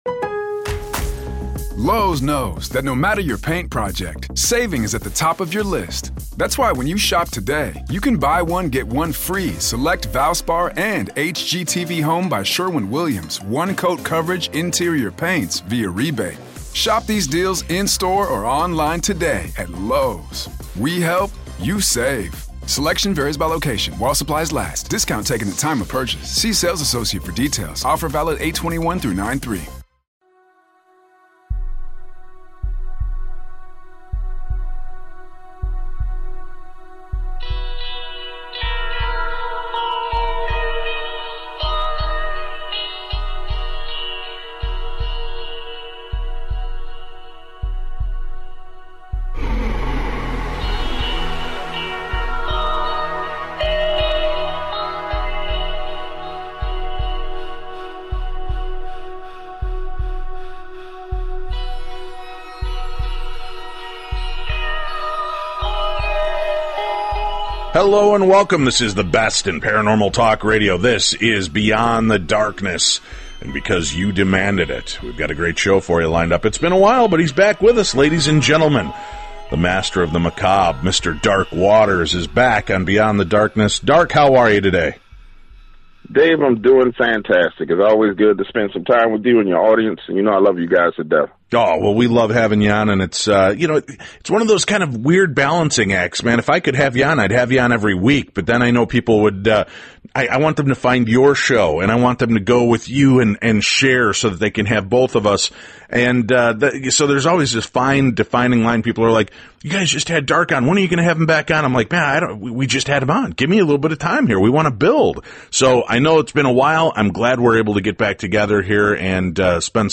This is the best in Paranormal Talk Radio.